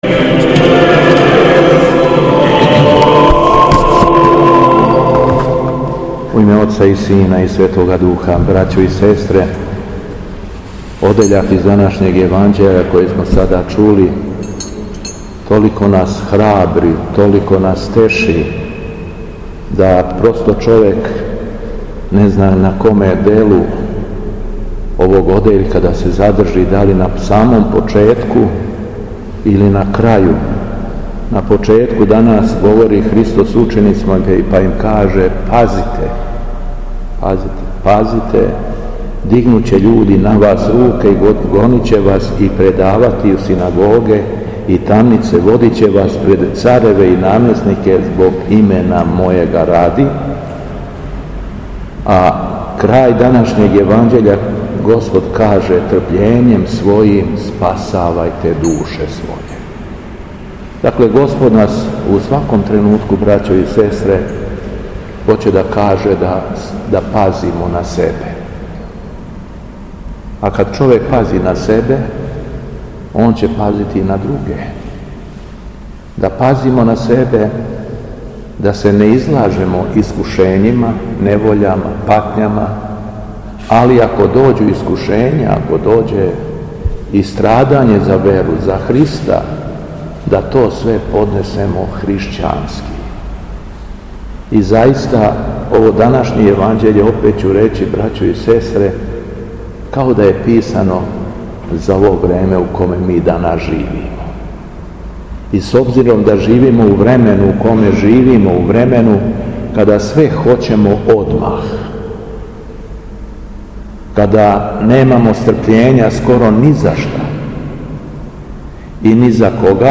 Беседа Његовог Високопреосвештенства Митрополита шумадијског г. Јована
У понедељак двадесети по Духовима, када наша Света Црква прославља свете мученике Сергија и Вакха, Његово Високопреосвештенство Митрополит шумадијски Господин Јован служио је свету архијерејску литуригију у храму Светога Саве у крагујевачком насељу Аеродром.